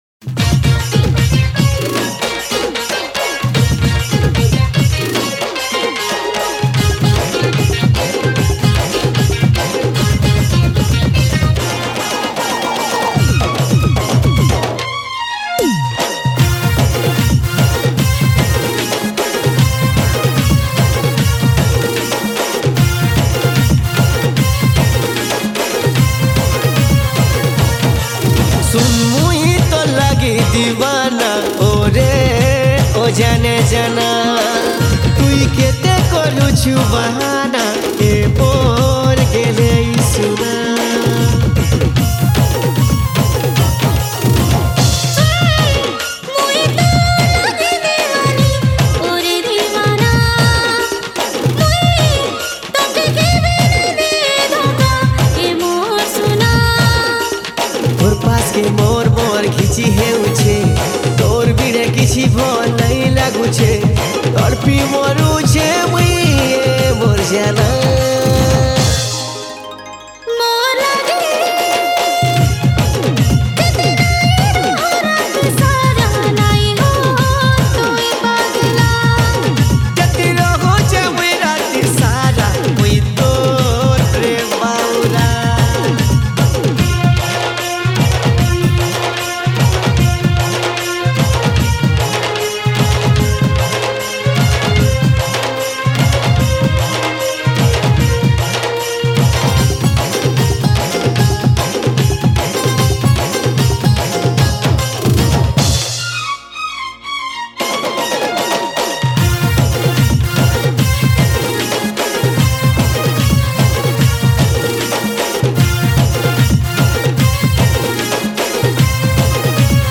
Home  / New Sambalpuri Song 2024